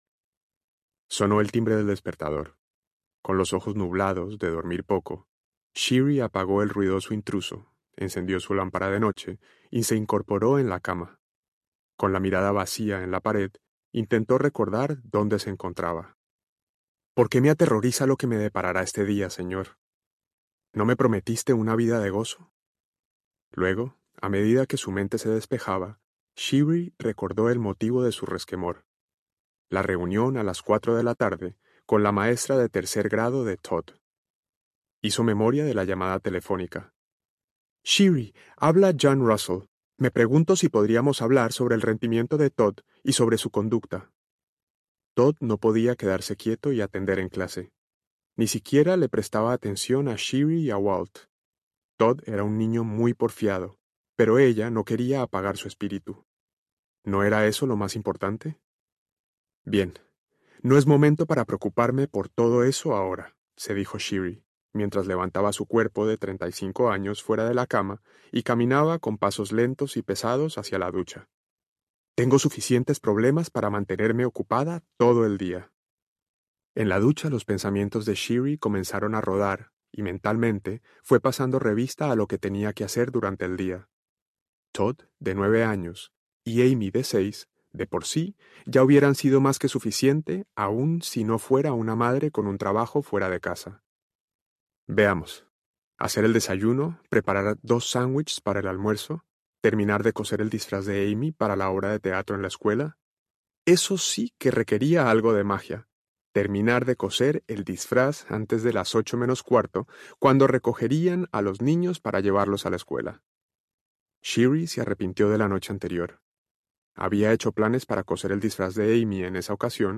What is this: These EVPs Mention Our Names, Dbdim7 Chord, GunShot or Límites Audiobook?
Límites Audiobook